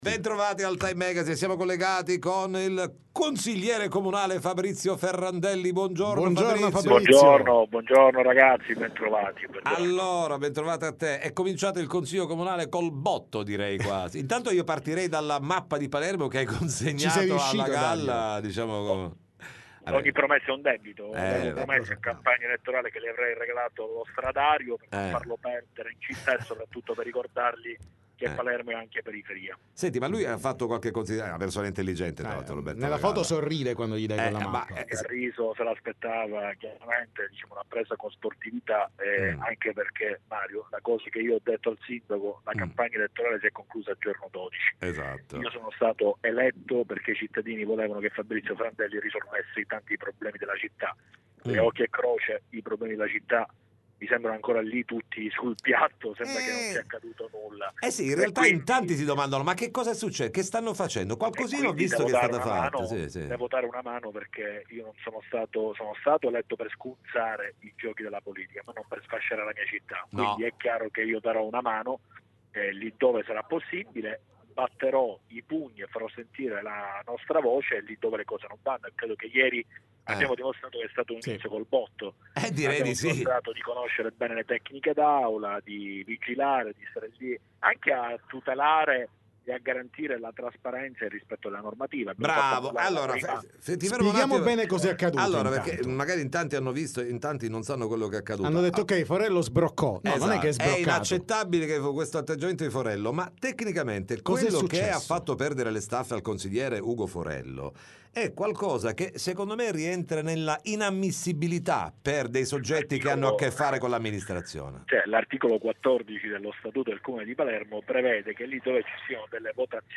TM Intervista Fabrizio Ferrandelli